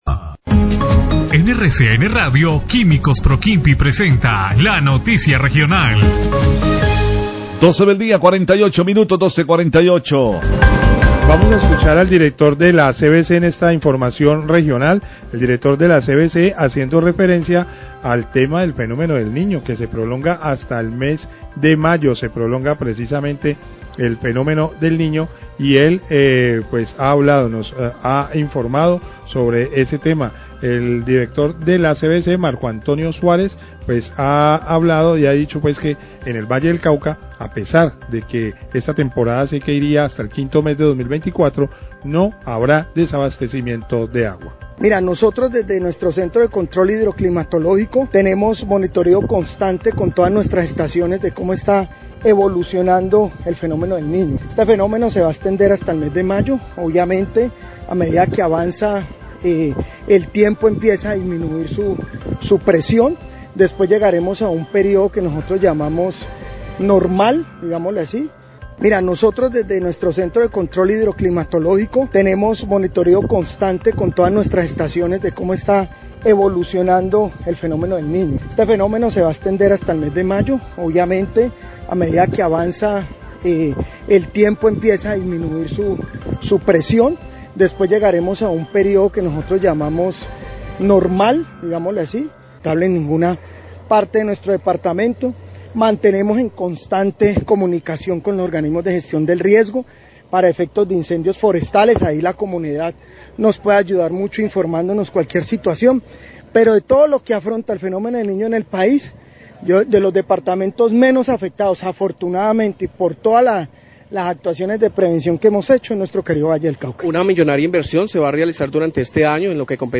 Radio
El director general de la CVC, Marco Antonio Suárez, habla de la situación del Fenómeno del Niño y el monitoreo constantes a las fuentes hidricas. Esto le permite afirma que no habrá desabastecimiento de agua en la región.